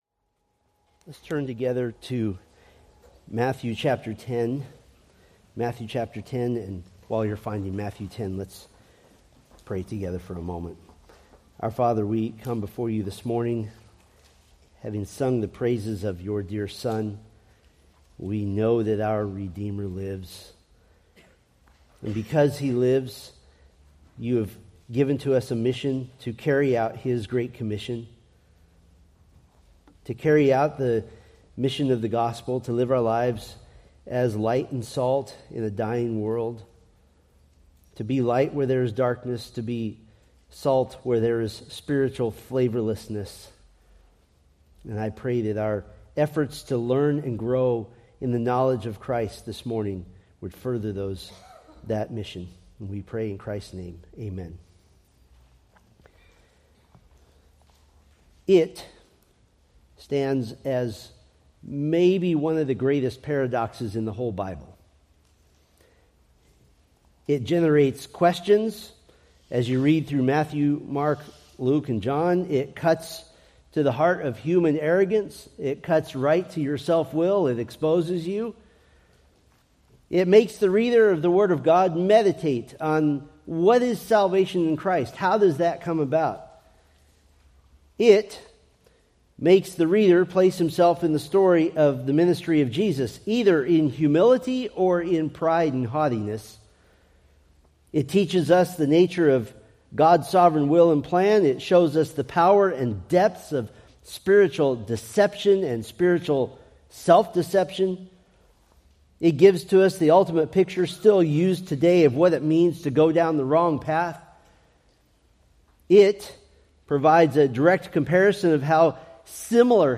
Preached April 6, 2025 from Matthew 10:4